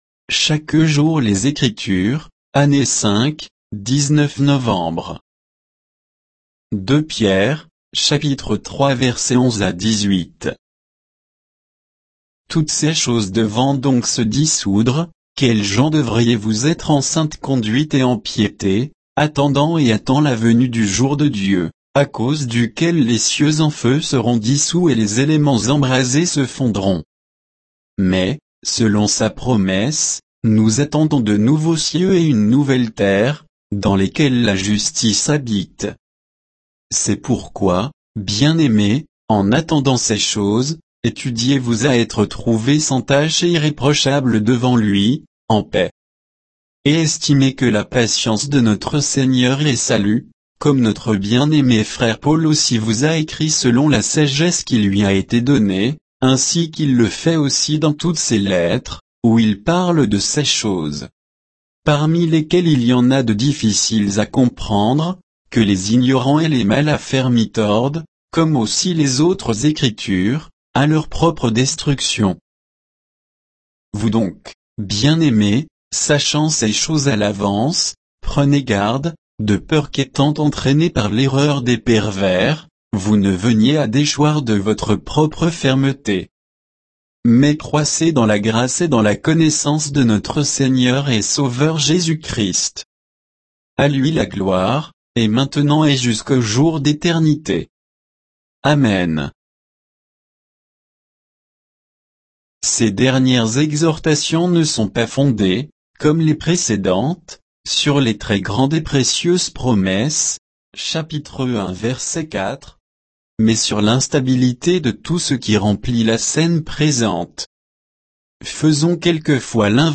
Méditation quoditienne de Chaque jour les Écritures sur 2 Pierre 3